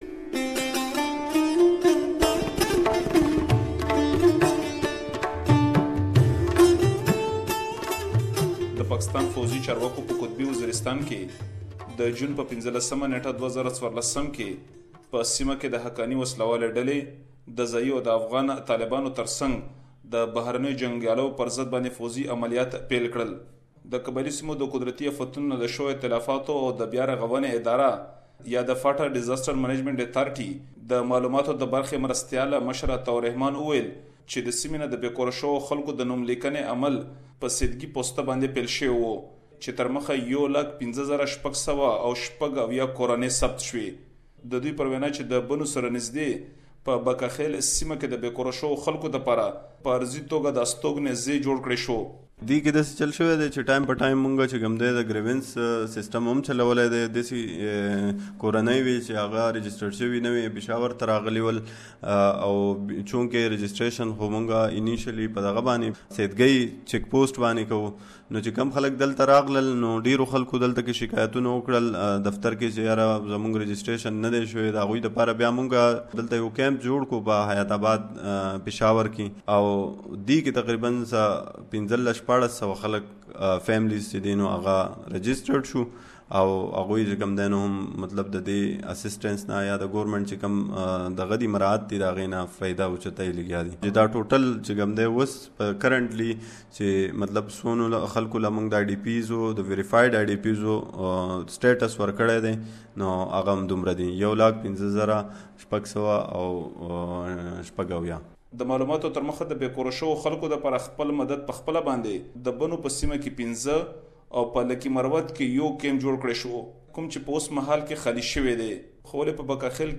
a report, and you can listen to the peoples concern and the authorities response.